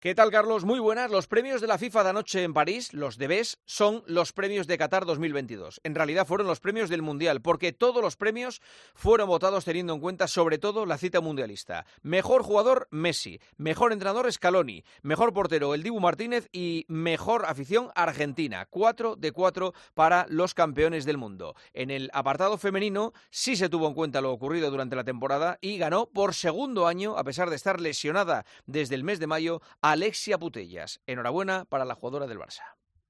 Juanma Castaño explica a Carlos Herrera por qué los premios The Best fueron "los de Qatar 2022"
El presentador de 'El Partidazo de COPE' analiza la actualidad deportiva en 'Herrera en COPE'